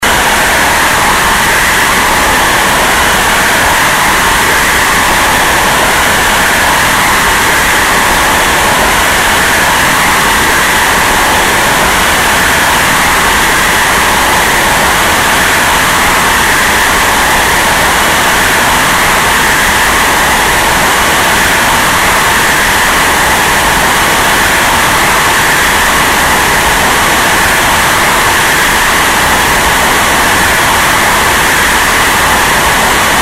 an output melodic and abrasive to the extreme.